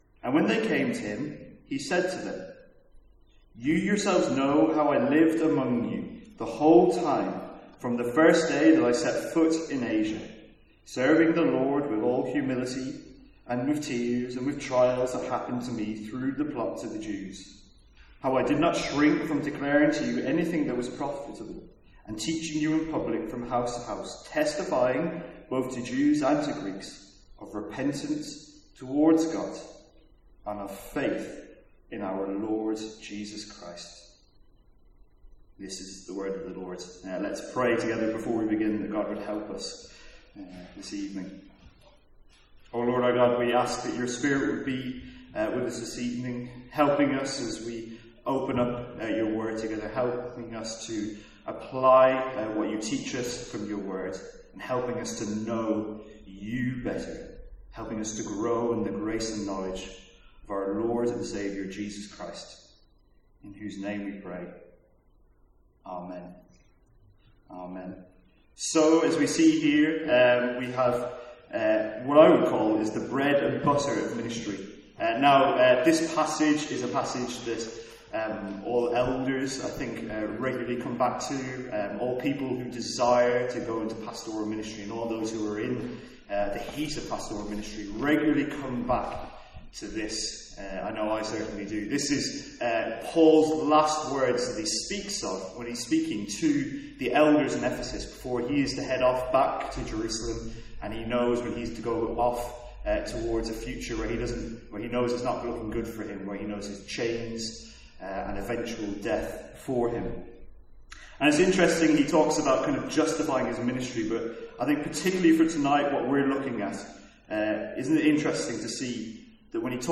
Sermons | St Andrews Free Church
From our evening series on the Order of Salvation.